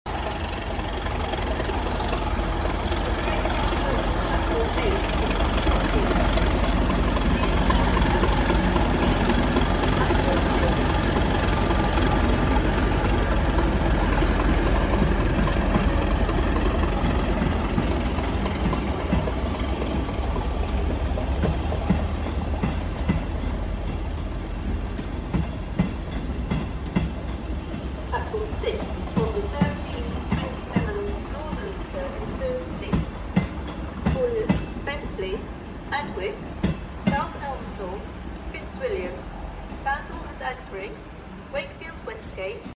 37 thrassshhhhh 🔥🔥🔥 Class 37 sound effects free download
37 thrassshhhhh 🔥🔥🔥 Class 37 today departing Doncaster dragging a former AWC 221 to Crofton, where it will join Grand Central on a short term lease.